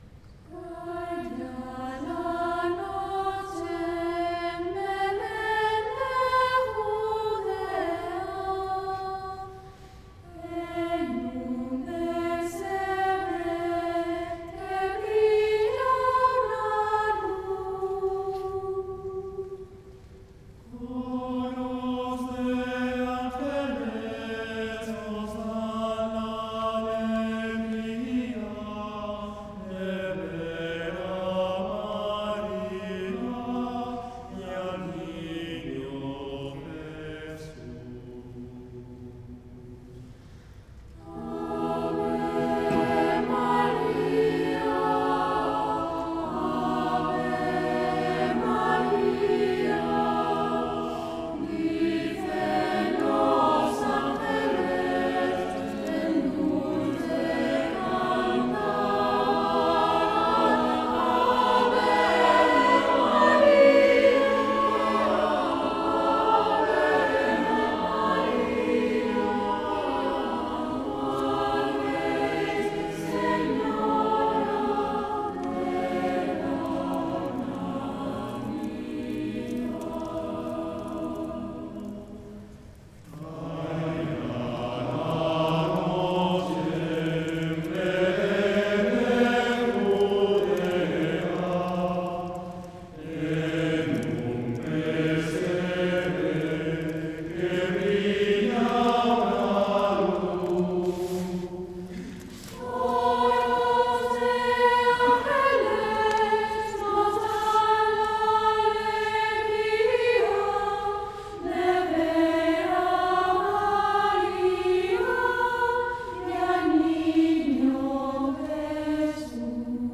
Es una  muestra de  canciones de diferentes estilos que hemos interpretado en los Conciertos (las grabaciones son en directo).